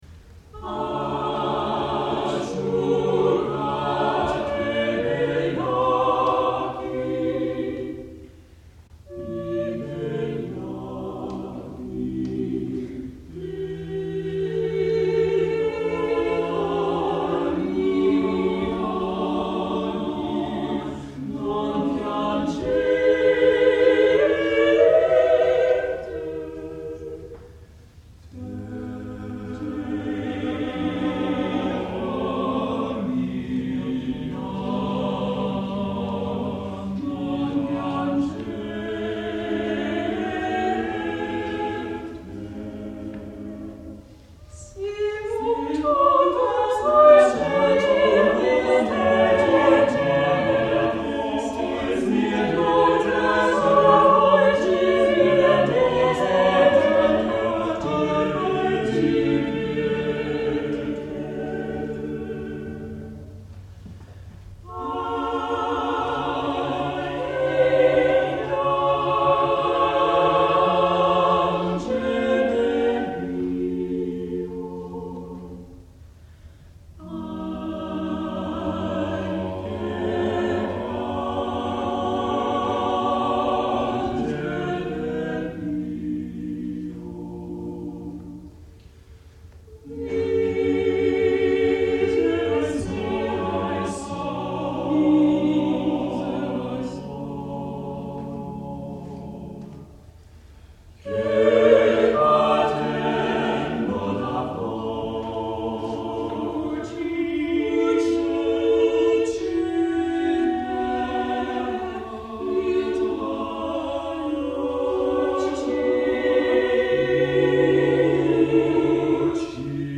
| Vocal Ensemble 'Food of Love' 1989